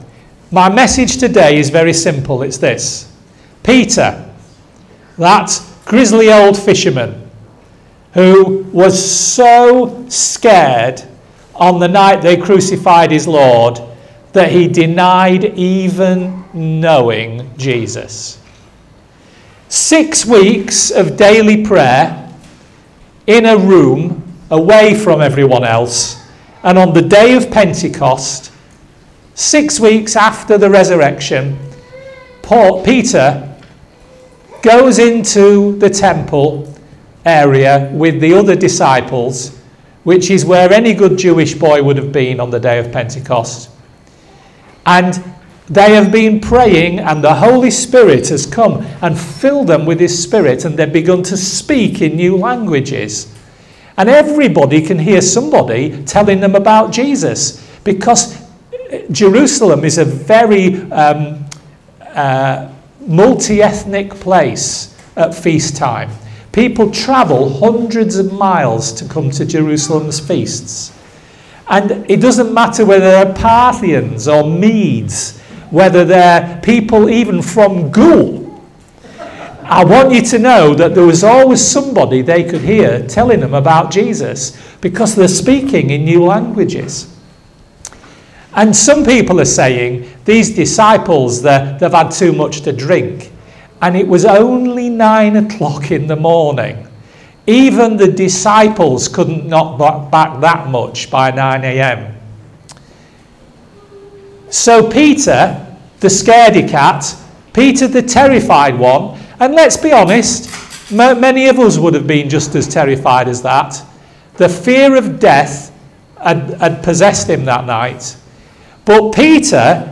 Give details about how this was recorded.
On Sunday February 26th we baptised some new converts